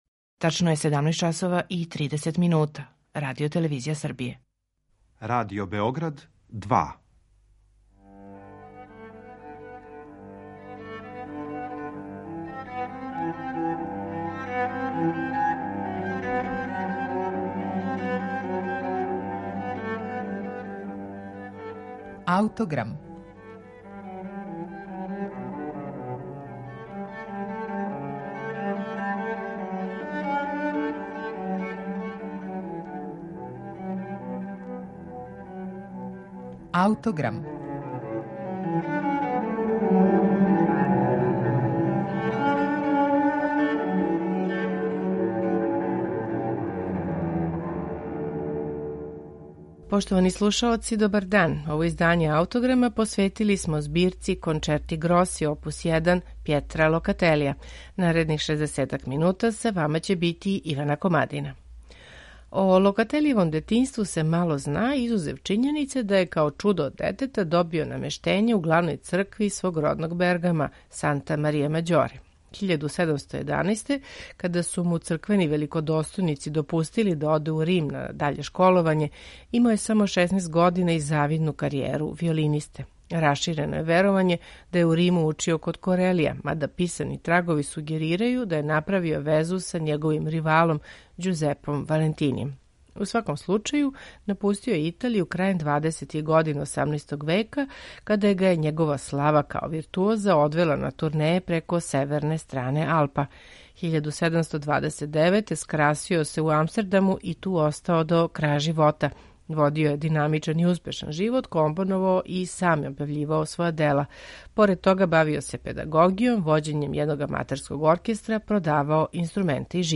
Локатели их је писао следећи модел који су већ успоставили Корели, Моси и Валентини, прецизно следећи њихову римску праксу и остајући веран архаичној структури инструменталне музике 17. века. У данашњем Аутограму шест концерата из ове збирке слушаћемо у интерпретацији ансамбла The Raglan Baroque Players , под управом Елизабет Волфиш и Николаса Кремера.